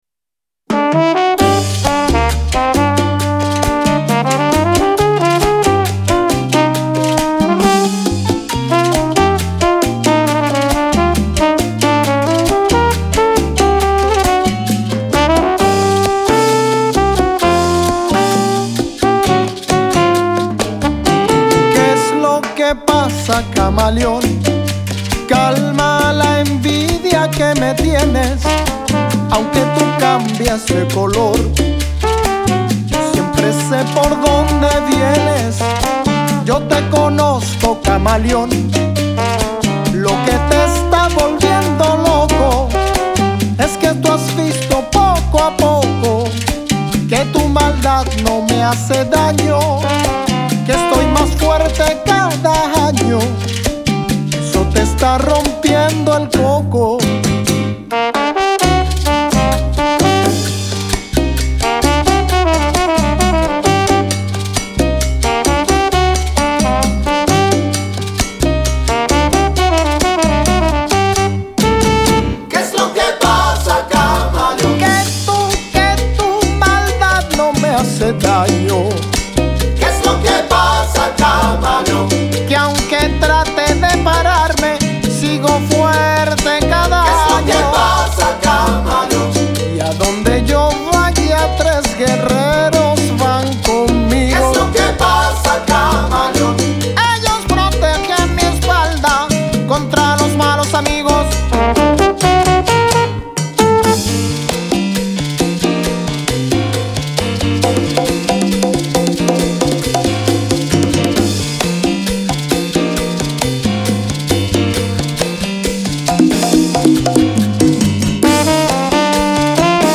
Salsa pa'gozar
chant
congas
timbales
piano
basse
trompette
Latin Jazz